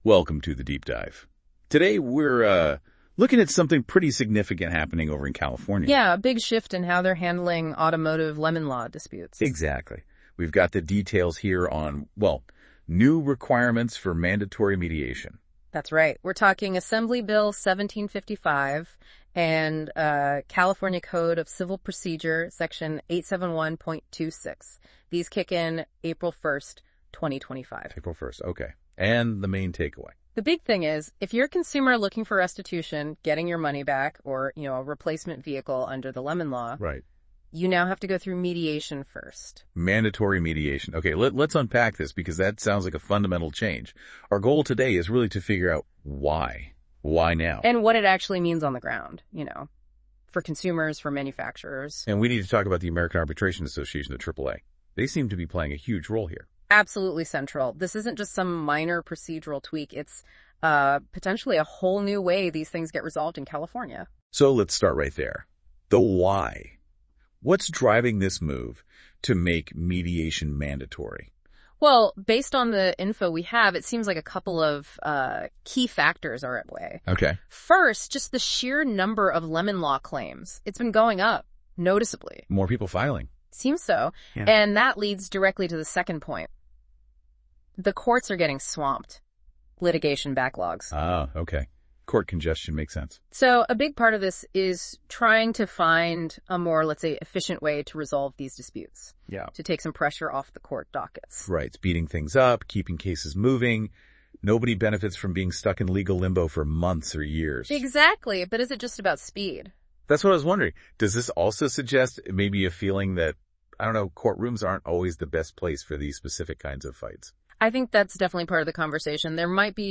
This tool transforms written content into dynamic audio discussions, where AI hosts summarize key insights, link related topics and create an engaging conversation.
Click here to listen to the AI-generated podcast summary of this article